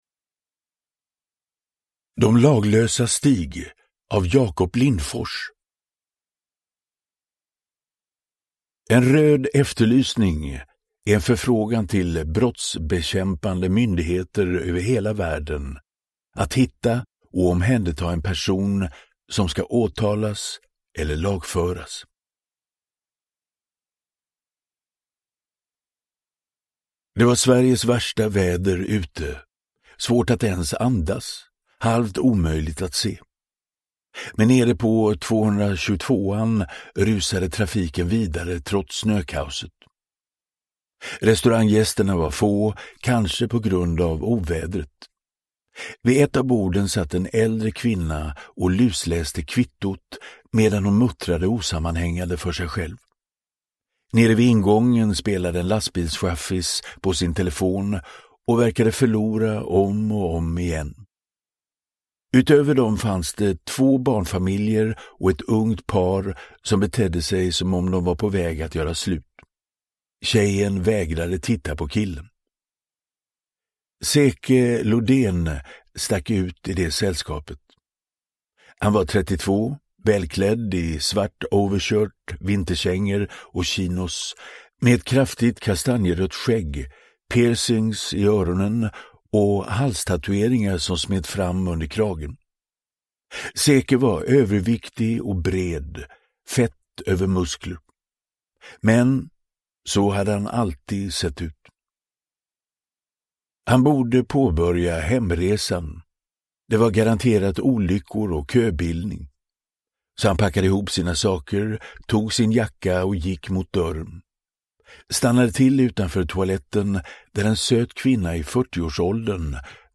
De laglösas stig – Ljudbok
Uppläsare: Magnus Roosmann